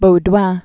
(bō-dwăɴ) 1930-1993.